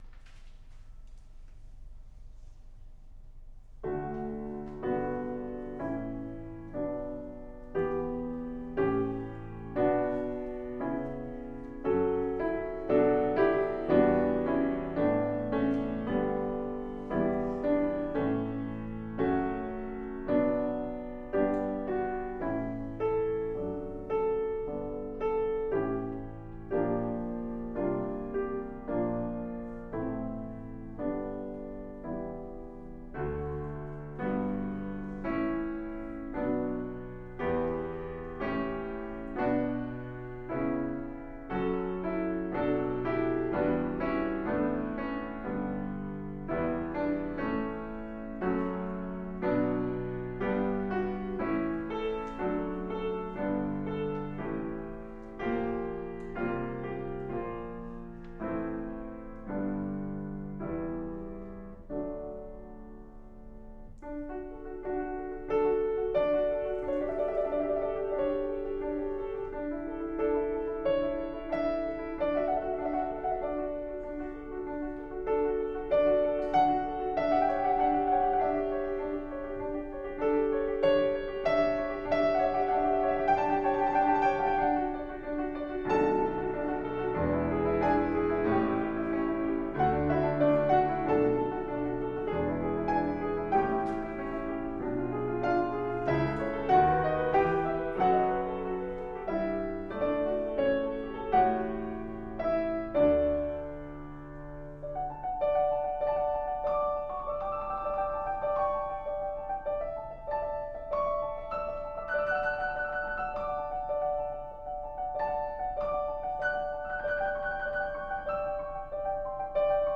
Love Story for Two Pianos
Duet / 2010
Movement 4: Aria